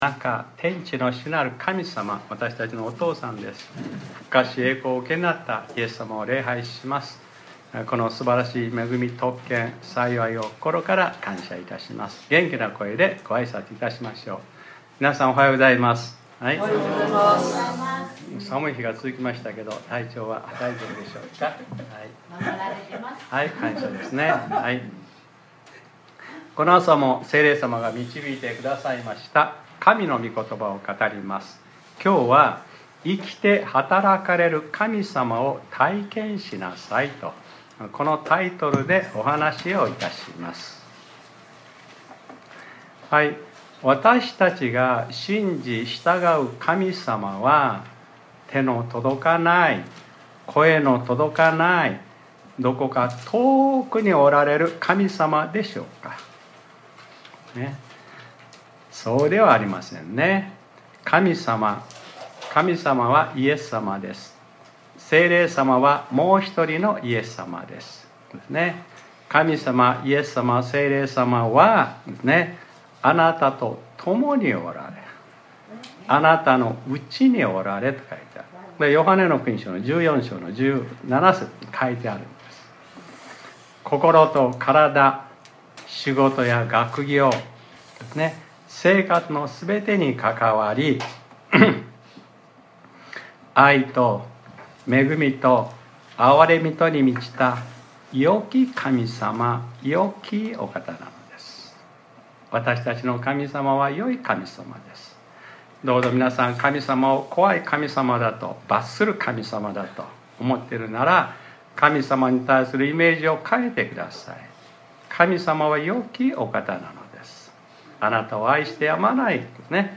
2025年02月09日（日）礼拝説教『 生きて働かれる神様を体験しなさい 』 | クライストチャーチ久留米教会